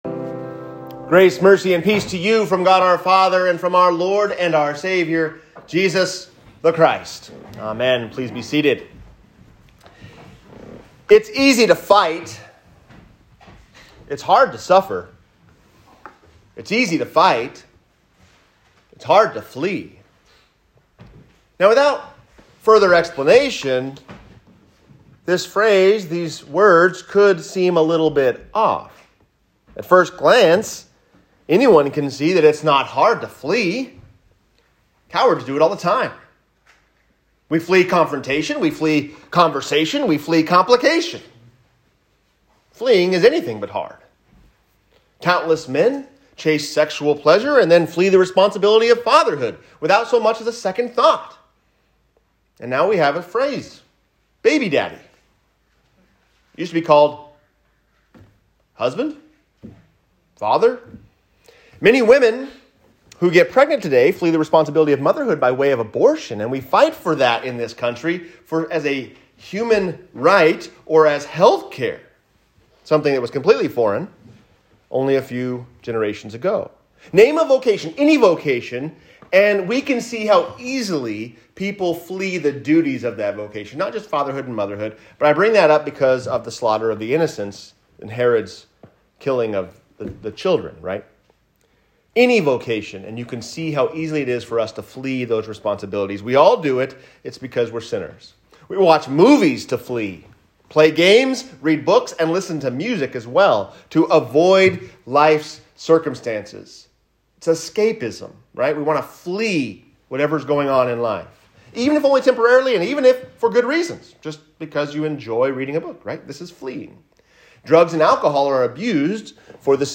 Suffering and Fleeing | Sermon
1-2-22-sermon_christmas-2.m4a